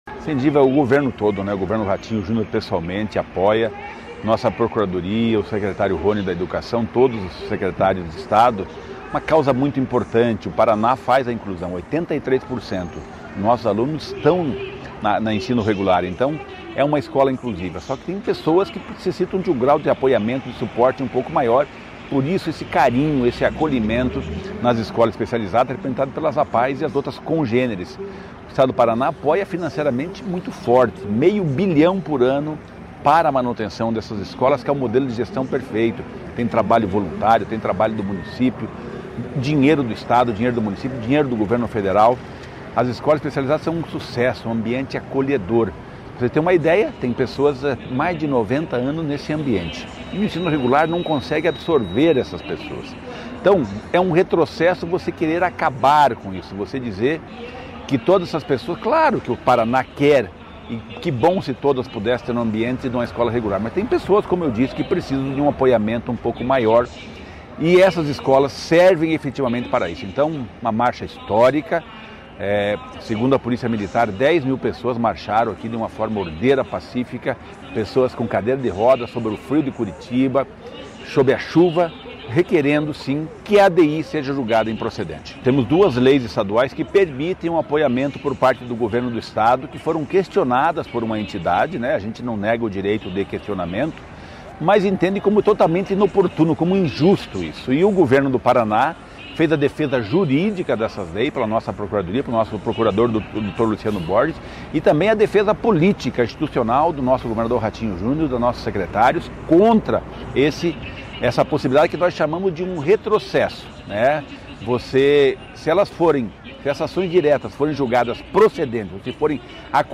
Sonora do secretário Estadual do Desenvolvimento Social e Família, Rogério Carboni, sobre a caminhada em defesa das escolas especializadas